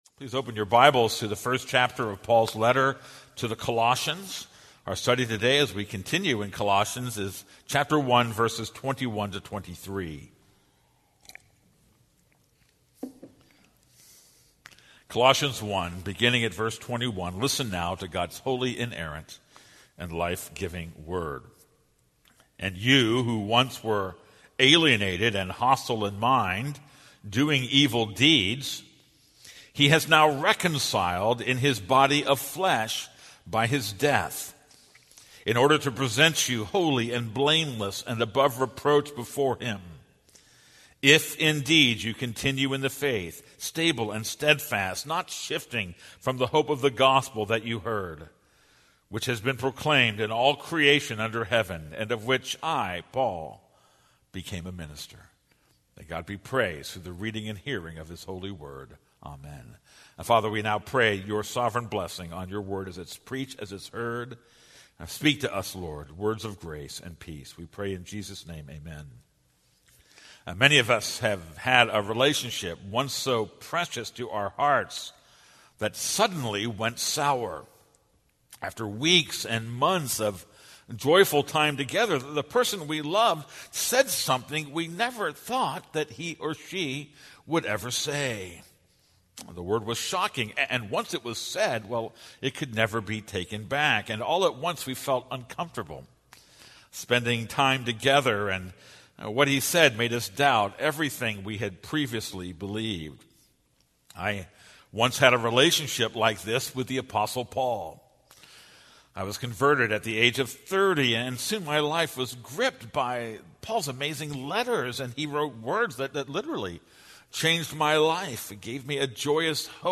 This is a sermon on Colossians 1:21-23.